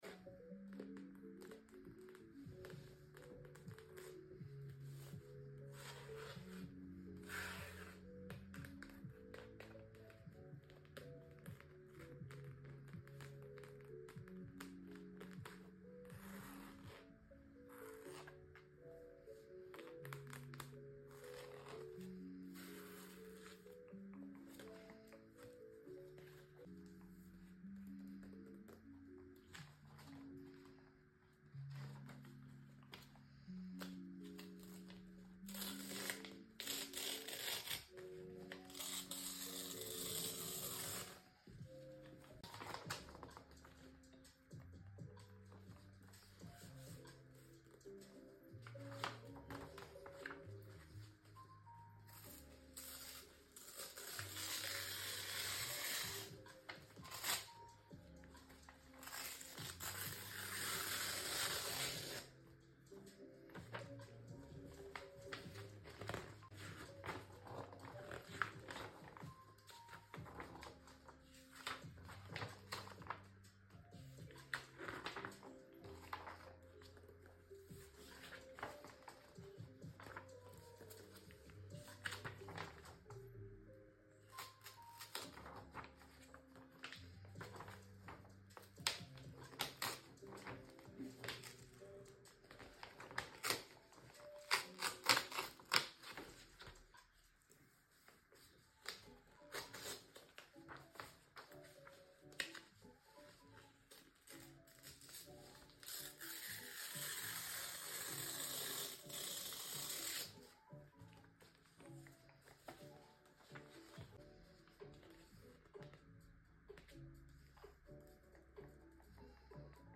do you like scrapping sounds sound effects free download